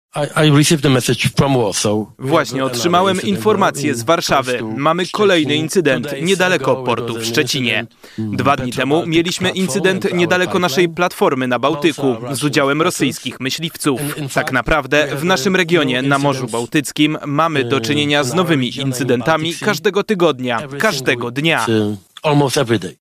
Mamy kolejny incydent w pobliżu portu w Szczecinie – powiedział w czwartek w Kopenhadze premier Donald Tusk. Według szefa polskiego rządu chodzi o rosyjskie jednostki.
Tusk wziął udział w panelu razem z prezydentką Mołdawii Maią Sandu, premierem Wielkiej Brytanii Keirem Starmerem i francuskim prezydentem Emmanuelem Macronem.